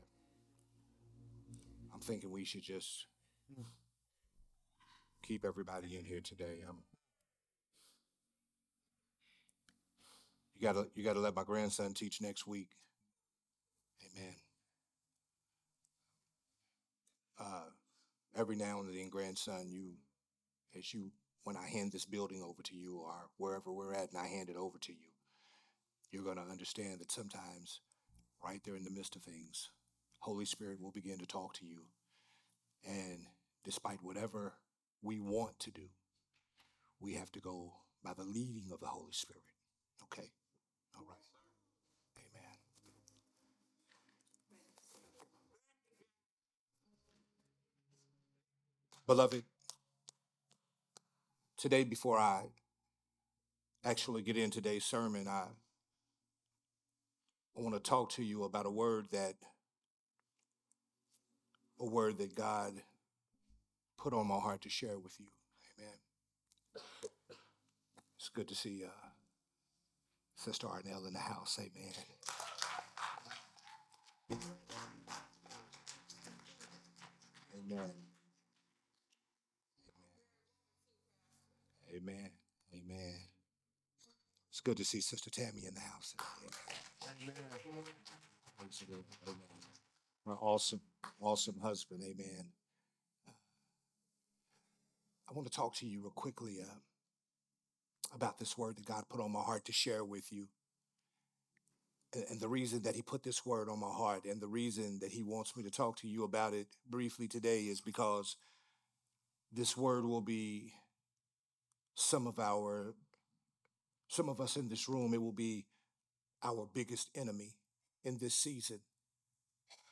recorded at Growth Temple Ministries on Sunday, January 4, 2026.